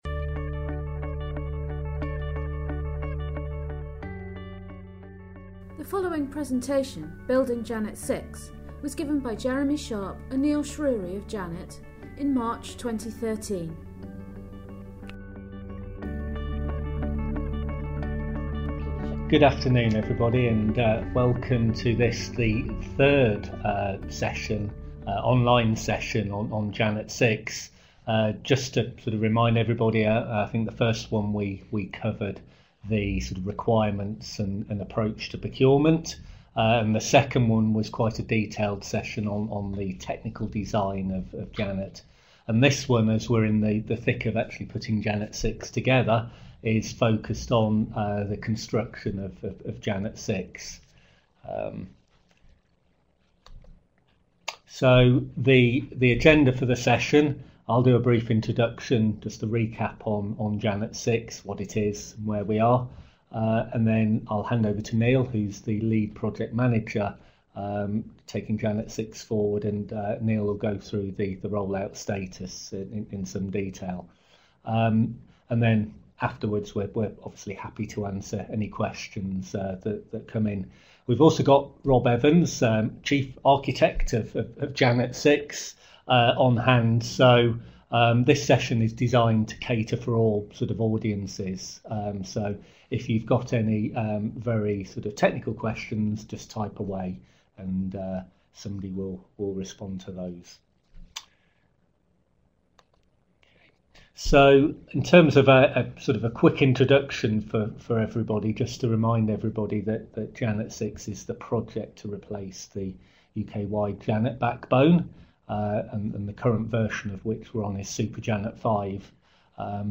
This is the third of a number of online briefings relating to Janet6 and provides the perfect opportunity to keep up to date with our progress to build the new generation of the Janet infrastructure.